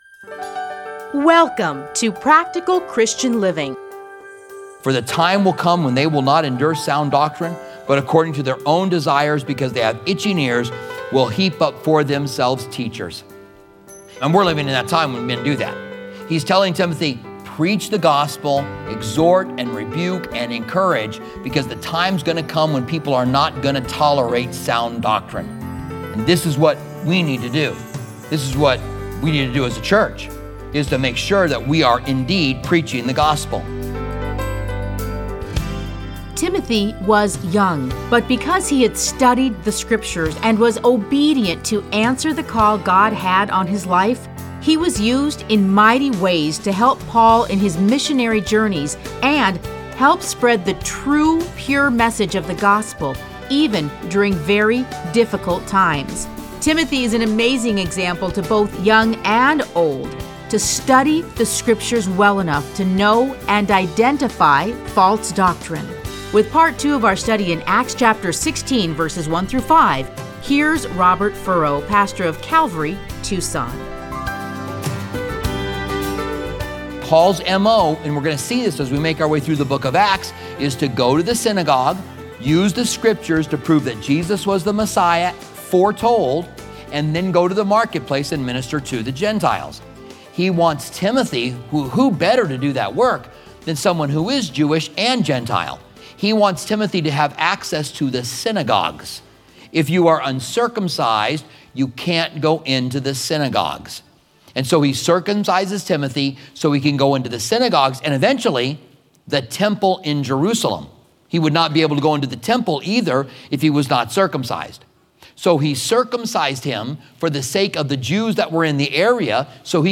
Listen to a teaching from Acts 16:1-5.